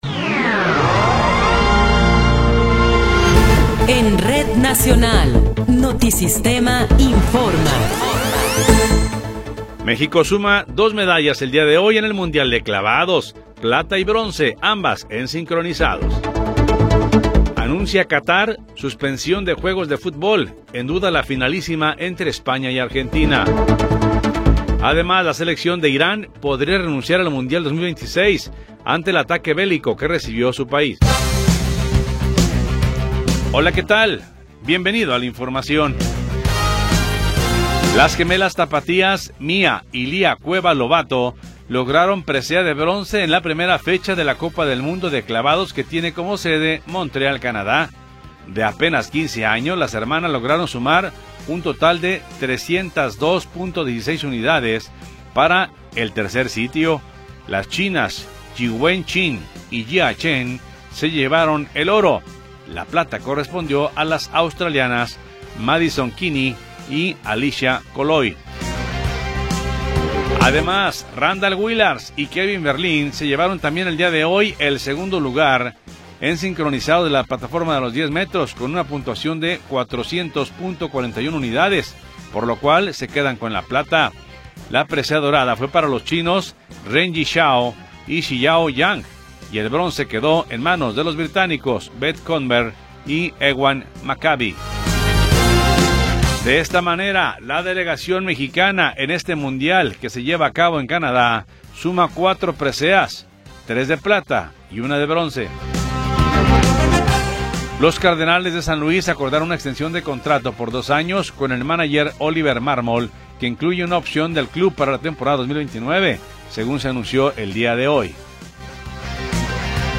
Noticiero 14 hrs. – 1 de Marzo de 2026
Resumen informativo Notisistema, la mejor y más completa información cada hora en la hora.